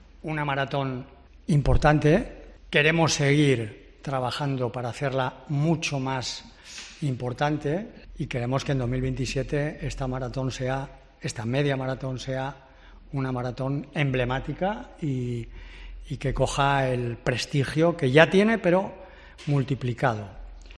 El concejal de Deportes Félix Brocate, explica la importancia de la Media Maratón Ciudad de Zaragoza